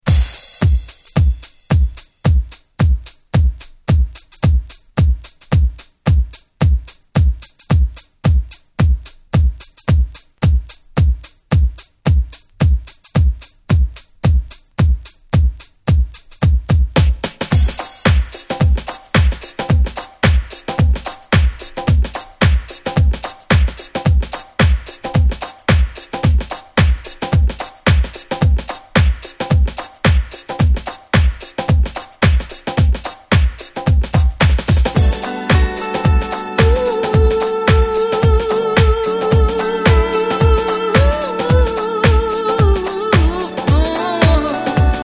未だにPlayすると30代は激盛り上がりのウルトラダンスナンバー！！
キャッチー系